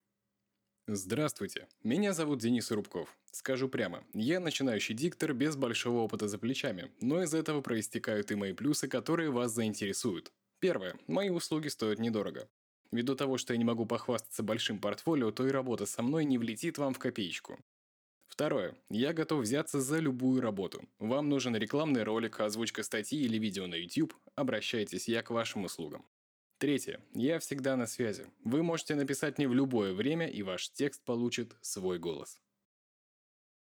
AKG P120 Behringer U-Phoria Umc22